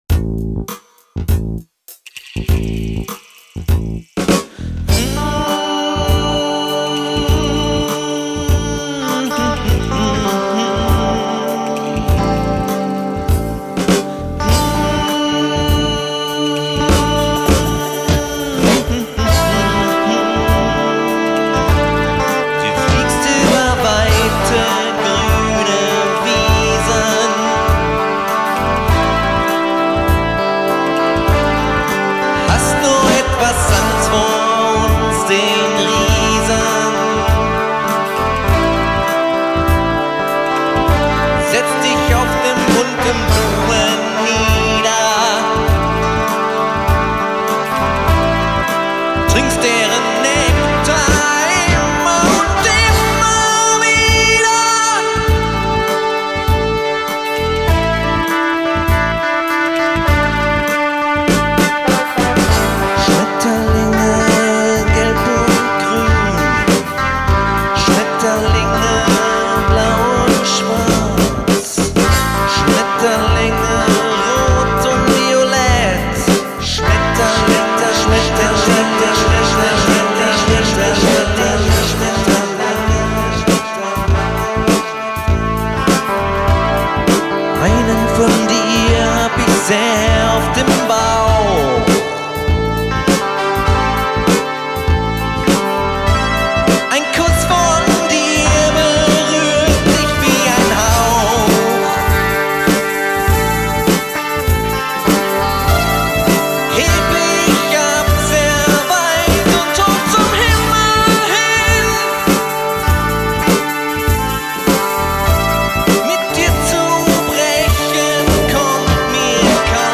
Ein eher poppiges Stück mit langgezogenen Bläsern und einer quirligen Gitarre....